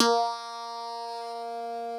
genesis_bass_046.wav